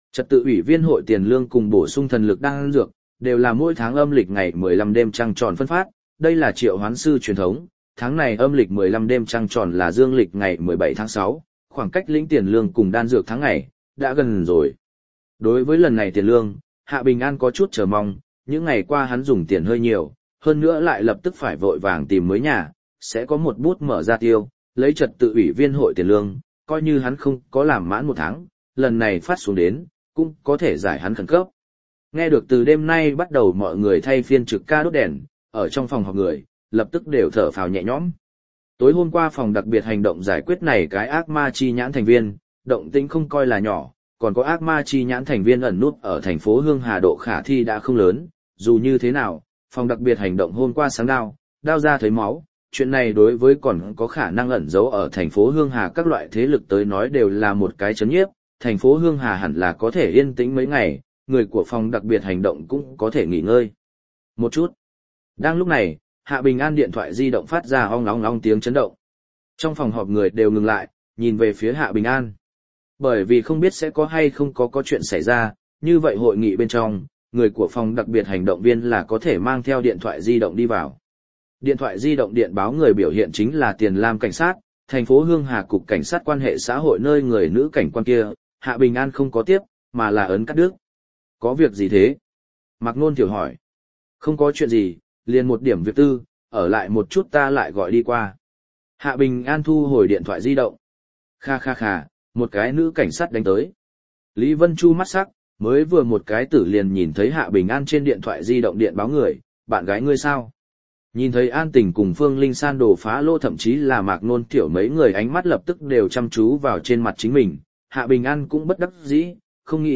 Hoàng Kim Triệu Hoán Sư Audio - Nghe đọc Truyện Audio Online Hay Trên AUDIO TRUYỆN FULL